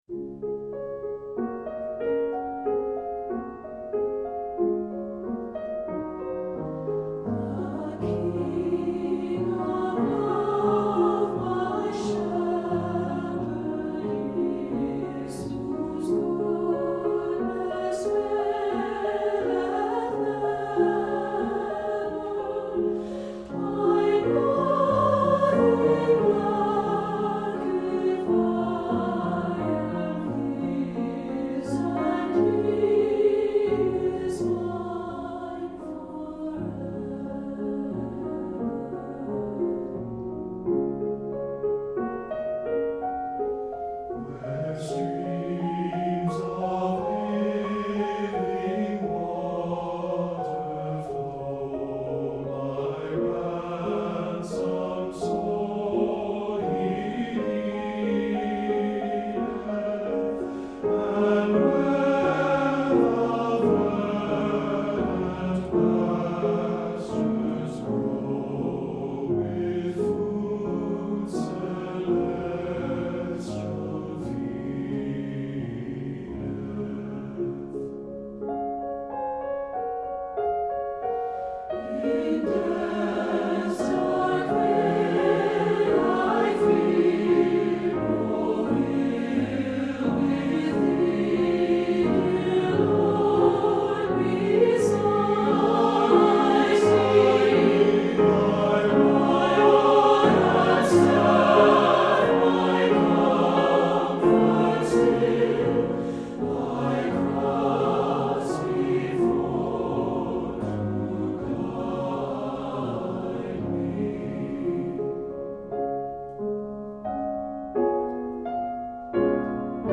Composer: Spirituals
Voicing: TTB